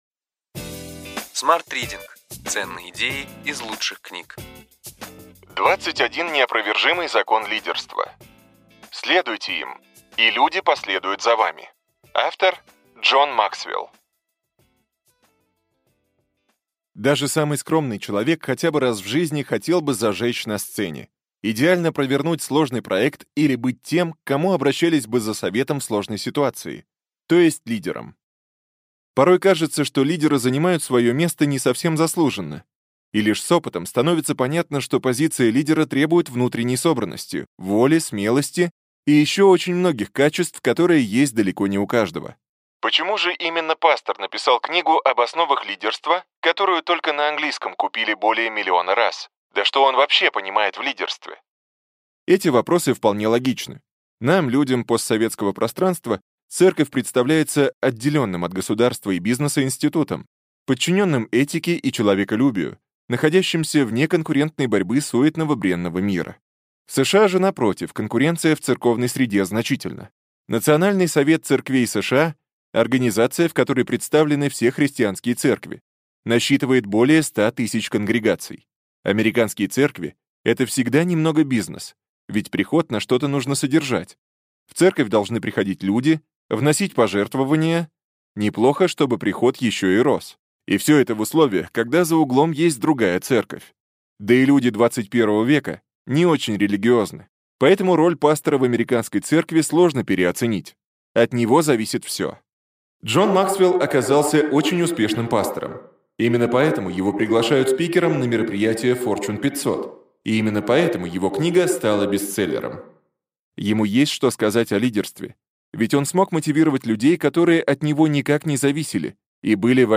Аудиокнига Ключевые идеи книги: 21 неопровержимый закон лидерства.